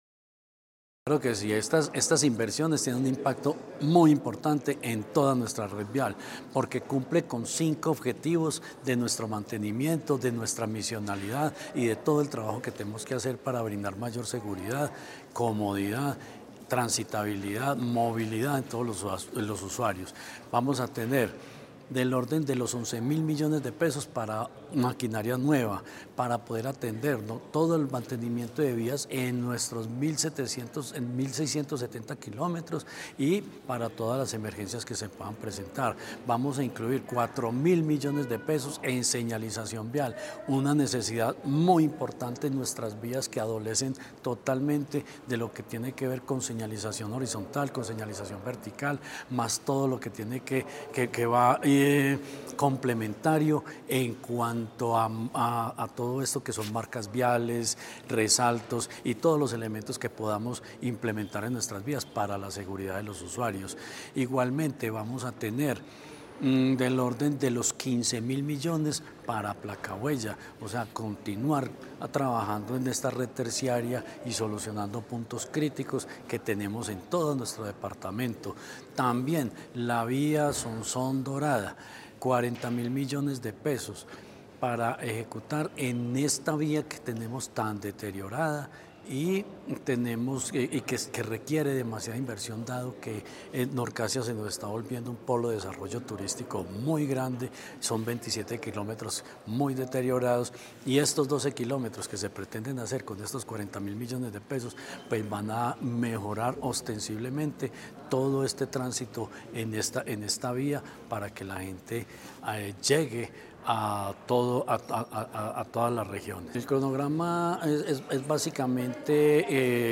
Secretarios de Infraestructura, Jorge Ricardo Gutiérrez Cardona.